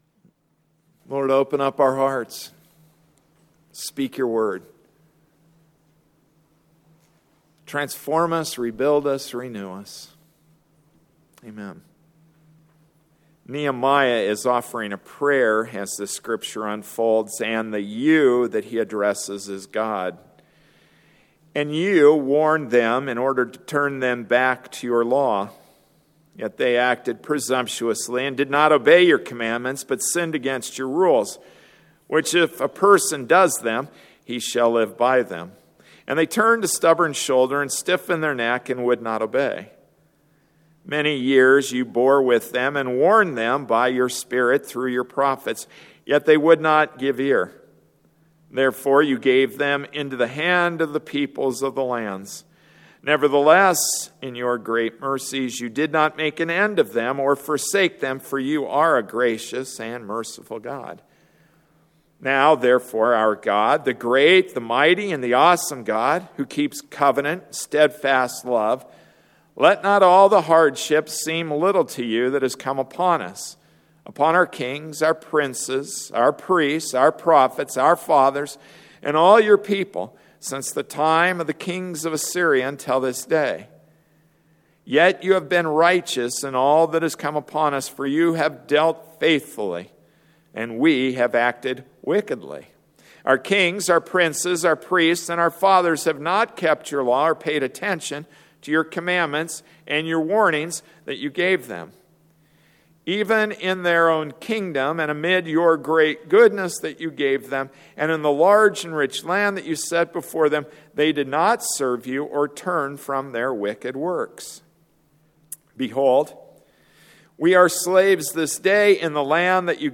October 13, 2013 Rebuild It Passage: Nehemiah 9:29-37 Service Type: Sunday Morning Service Rebuild It Ezra Ch1 King Cyrus issues a proclamation (538 b.c.) allowing Jews to return to Jerusalem.